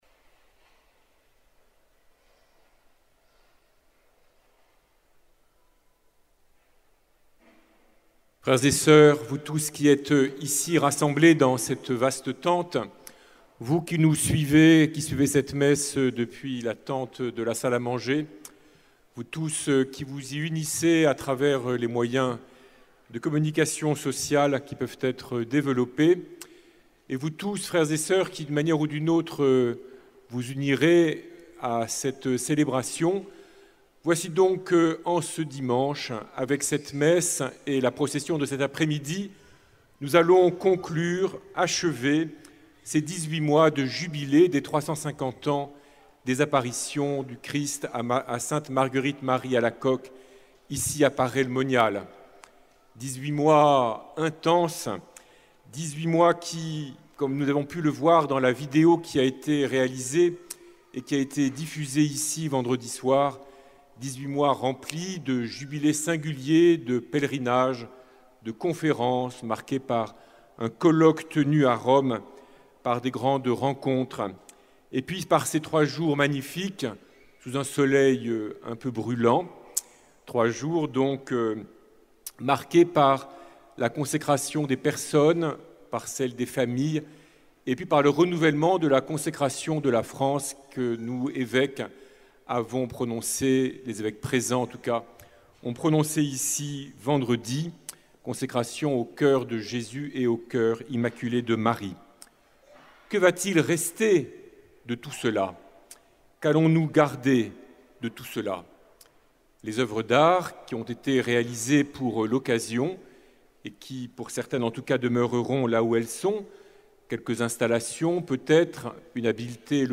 fetes_du_sacre-coeur_-_homelie_moulins_beaufort-.mp3